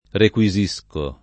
vai all'elenco alfabetico delle voci ingrandisci il carattere 100% rimpicciolisci il carattere stampa invia tramite posta elettronica codividi su Facebook requisire v.; requisisco [ rek U i @&S ko ], ‑sci — non requisizionare